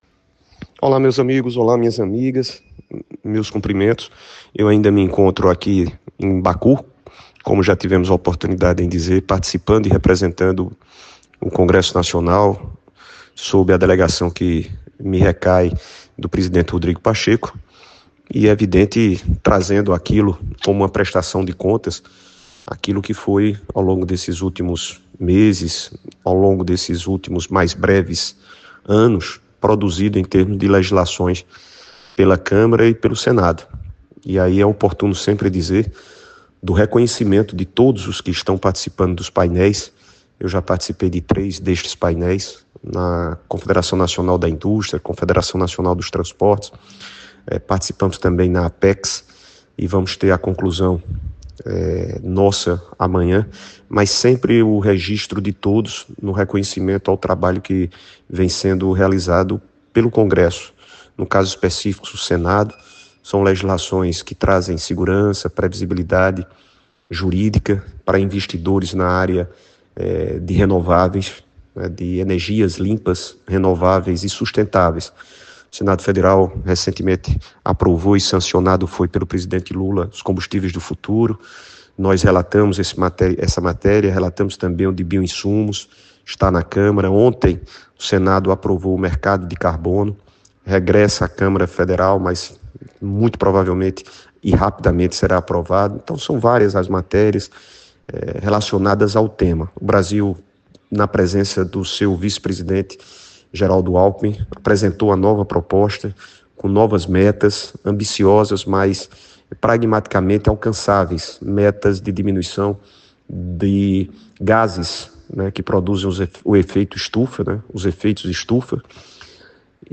Ele falou em um áudio enviado diretamente do país asiático que já fez parte do antigo Império Russo e também da ex-União Soviética, sobre os temas debatidos e a participação brasileira no encontro.